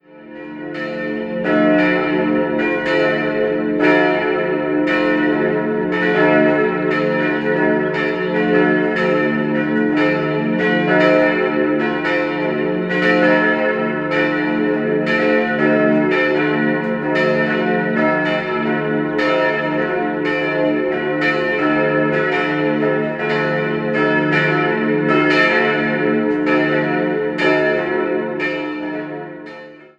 3-stimmiges Gloria-Geläut: es'-f'-as' Die beiden kleinere Glocken stammen noch aus spätgotischer Zeit.